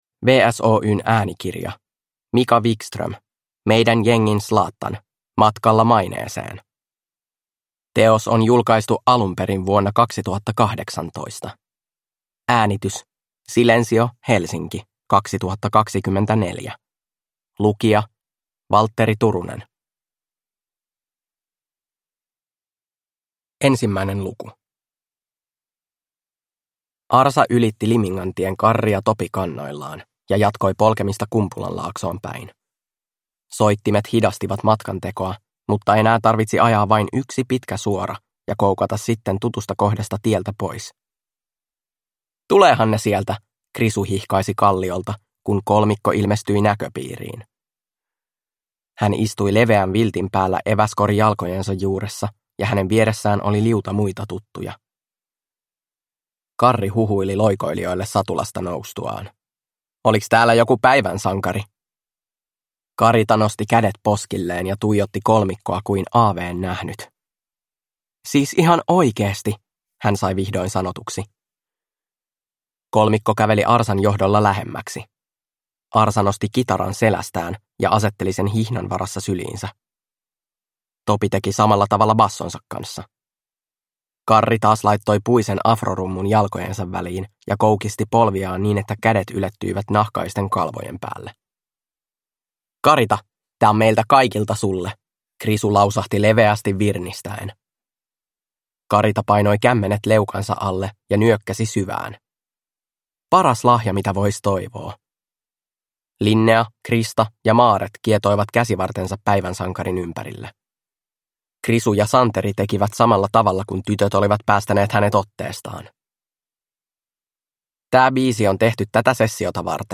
Meidän jengin Zlatan - matkalla maineeseen – Ljudbok